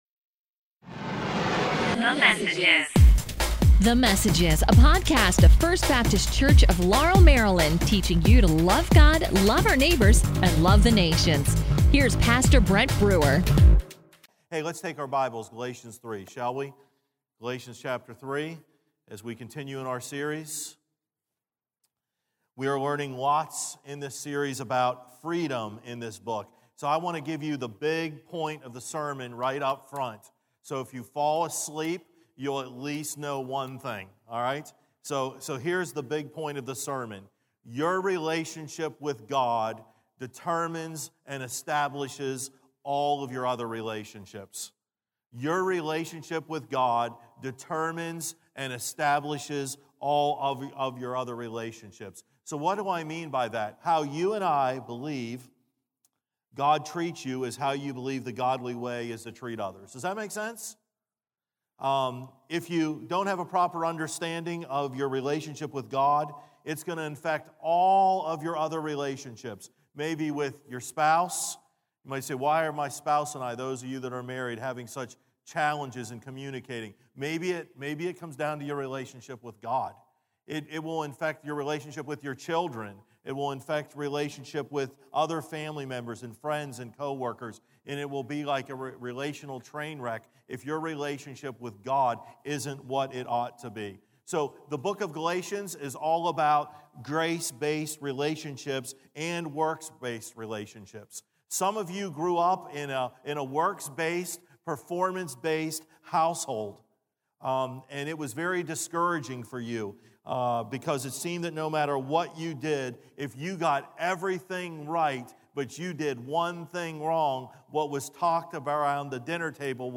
A message from the series "Church in the City."